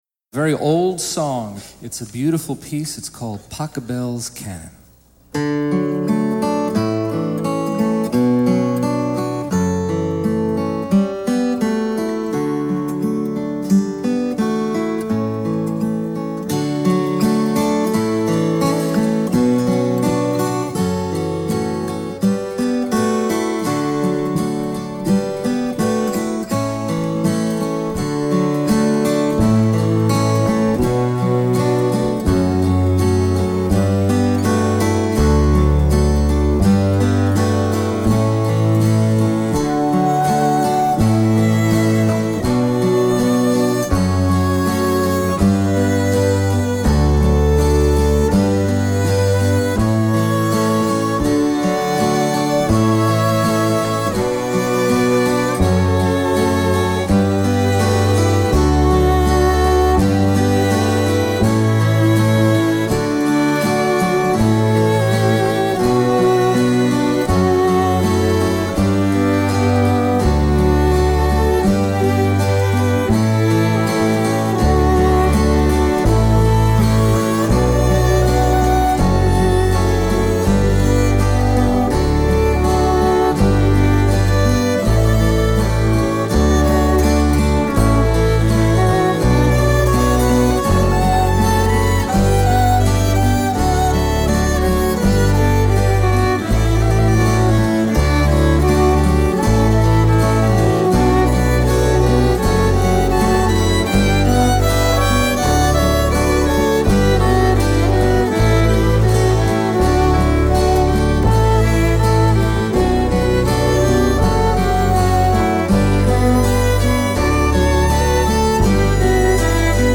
In  Act Two, Woody Guthrie, Pete Seeger and their wives dance to this folk version of the song.
07-Pachelbels-Canon-Live-Bonus.mp3